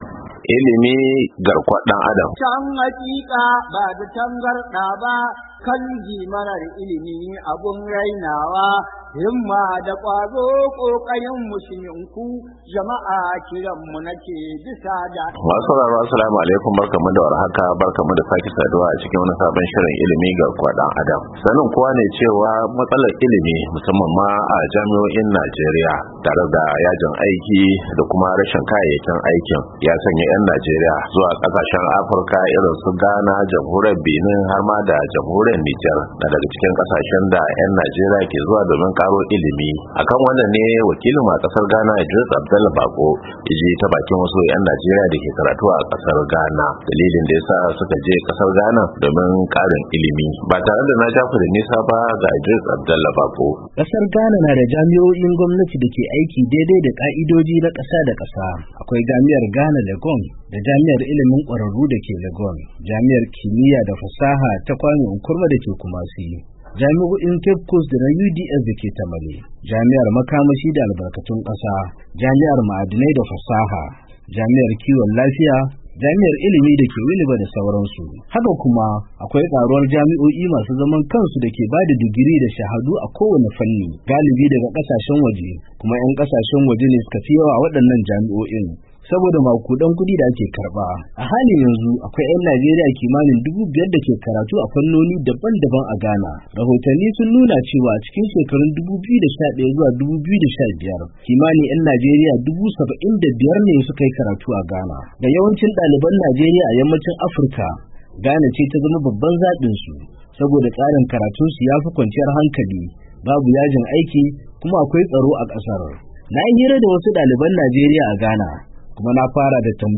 ILIMI GARKUWAR ‘DAN ADAM: Hira Da Dalibai ‘Yan Najeriya A Ghana Kan Batun Tsere Wa Najeriya A Fagen Ilimi - Agusta, 07, 2023